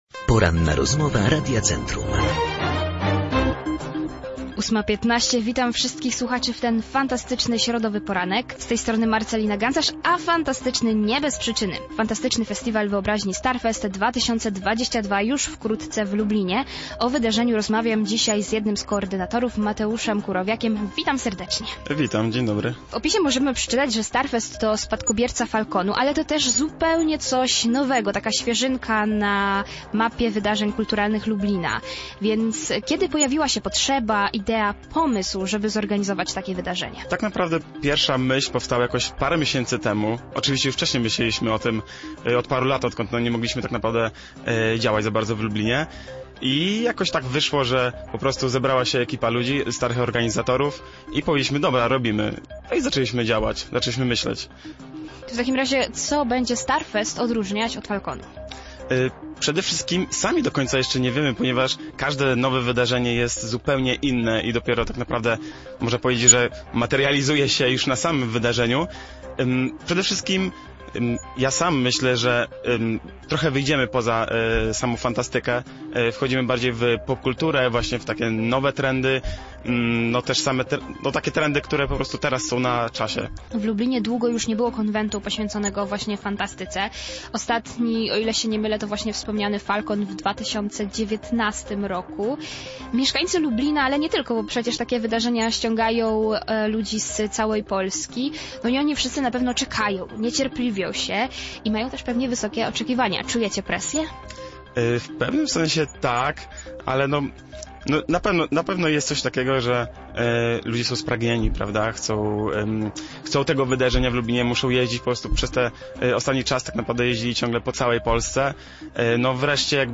O tym mówiliśmy podczas Porannej Rozmowy Radia Centrum.